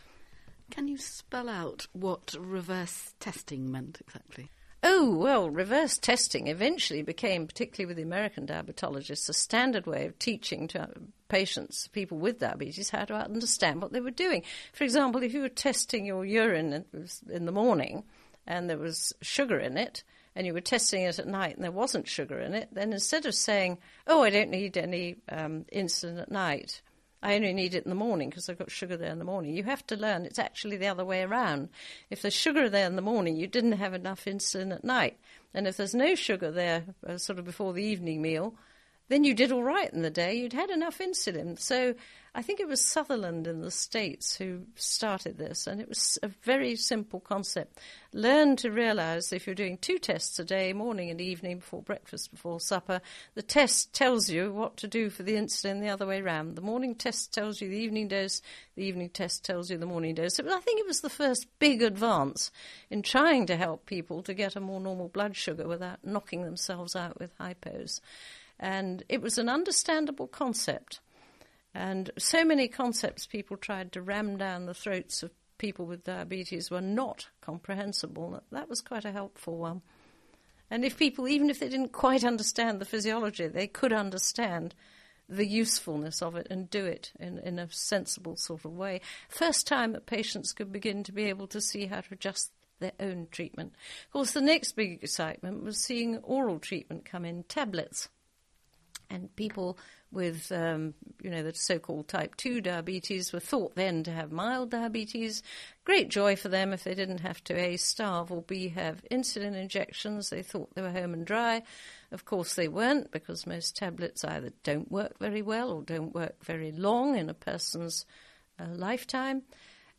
Here you can read through the whole transcript for this interview.